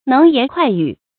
能言快語 注音： ㄣㄥˊ ㄧㄢˊ ㄎㄨㄞˋ ㄧㄩˇ 讀音讀法： 意思解釋： 能說會道，言詞敏捷爽利。